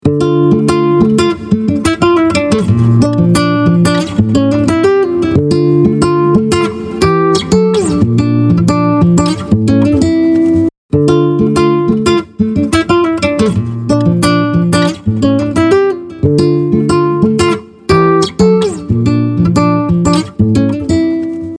Acoustic Guitar | Preset: Acoustic Presence (DRY→WET)
Articulate-Acoustic-Guitar-Acoustic-Presence-2.mp3